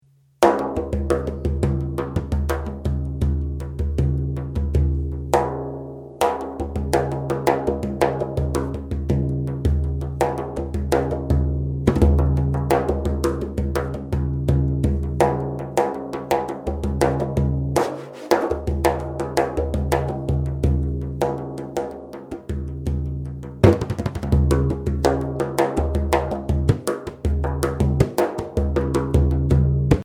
Voicing: Drum Method